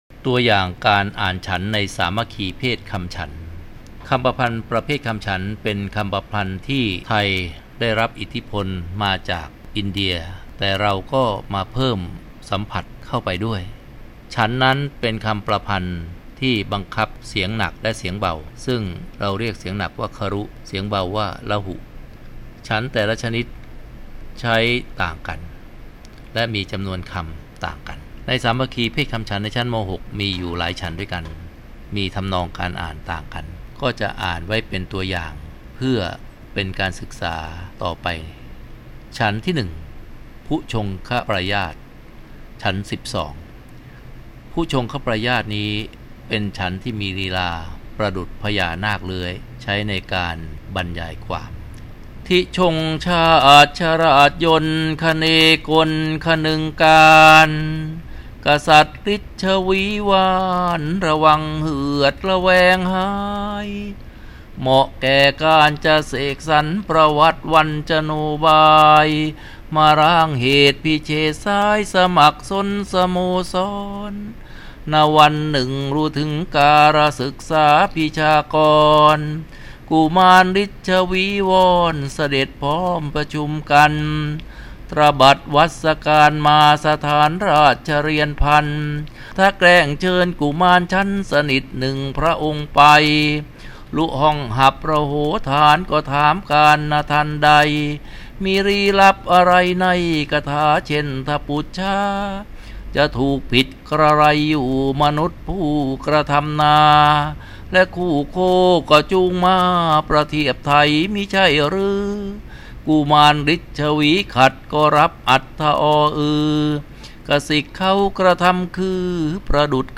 เสียงบรรยายสามัคคีเภทคำฉันท์